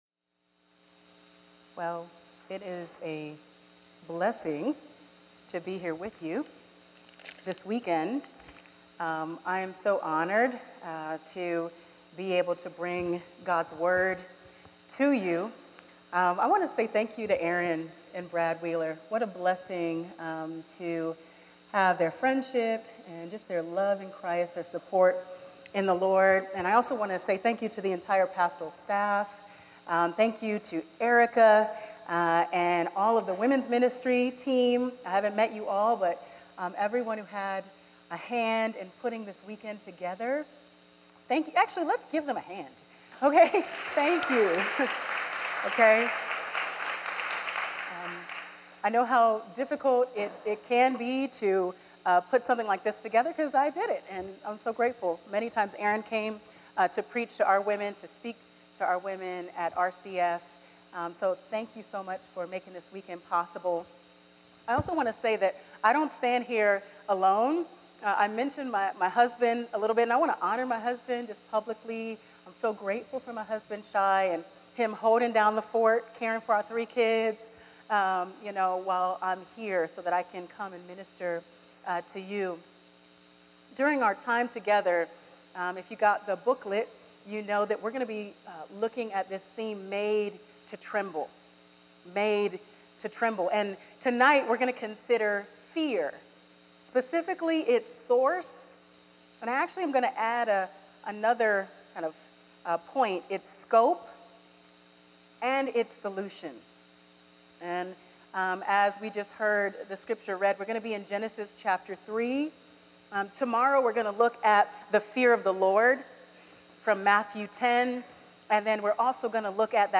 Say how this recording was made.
Women's Retreat | Session 1 - Fear: It’s Source and Solution